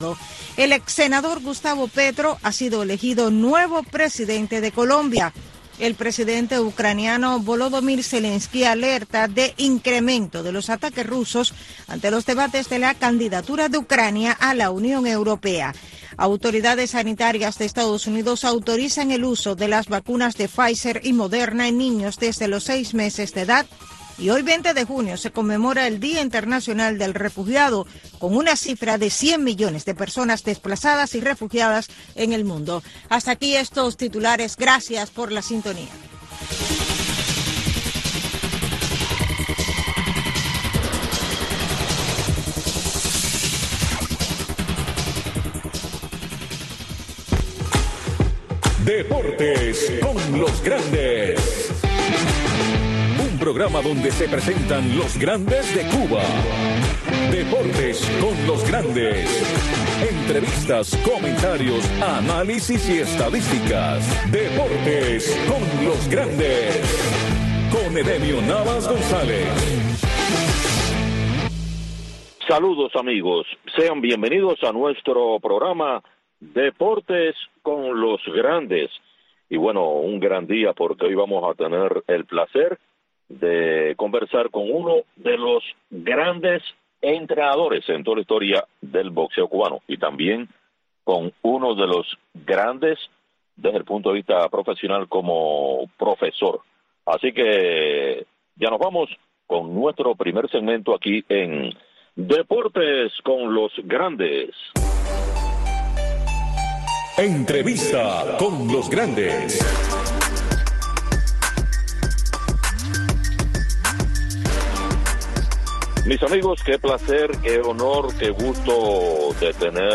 Entrevistas, comentarios y análisis de los grandes acontecimientos del deporte.